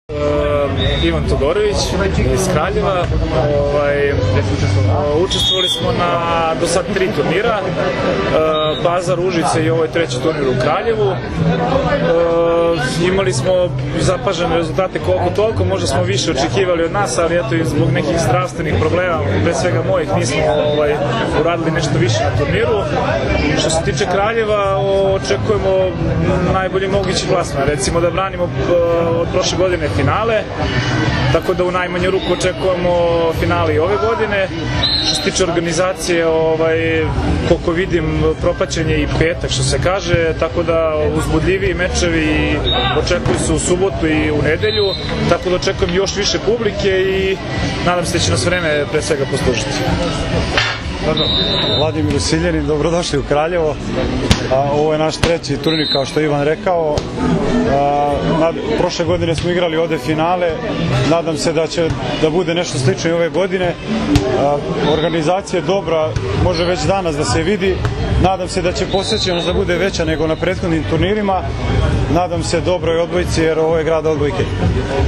Večeras je u Kraljevu održana konferencija za novinare